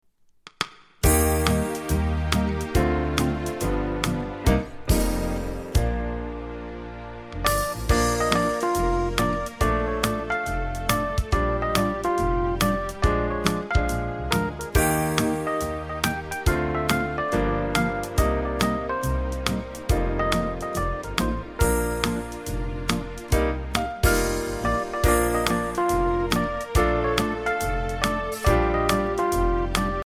instrumental clip